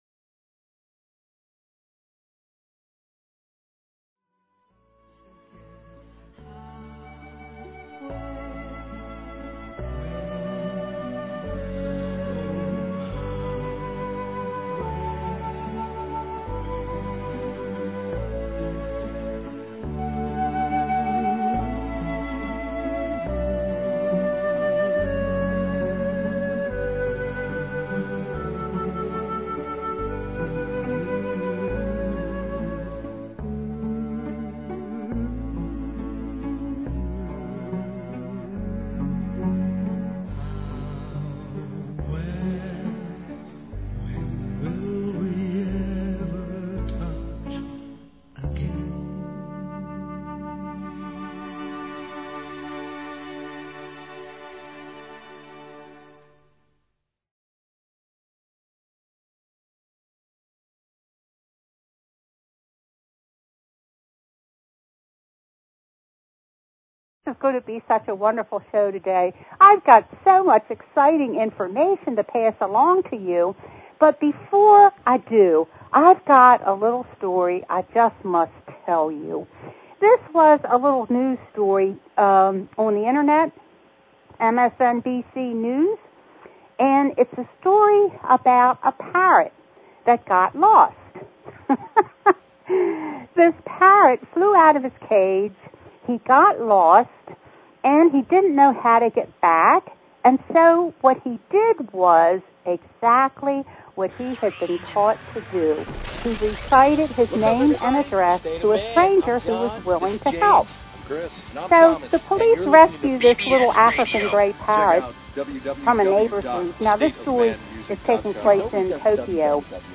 Talk Show Episode, Audio Podcast, The_Messenger and Courtesy of BBS Radio on , show guests , about , categorized as